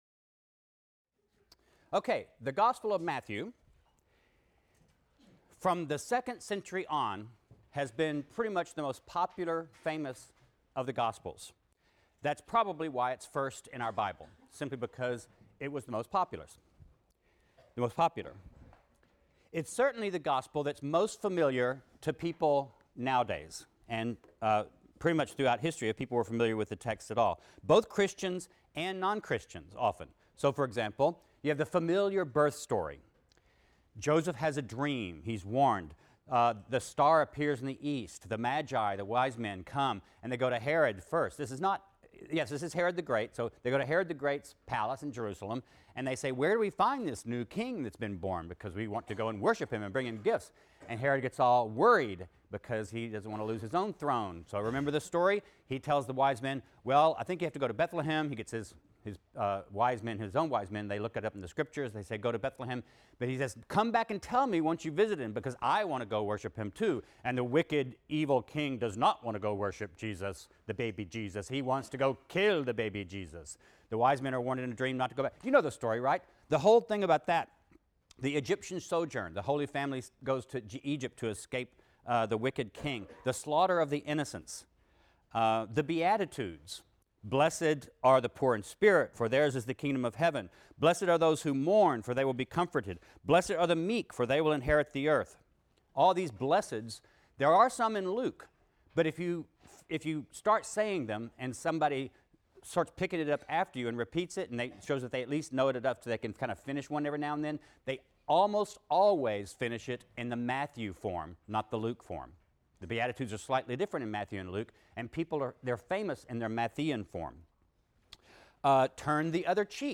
RLST 152 - Lecture 7 - The Gospel of Matthew | Open Yale Courses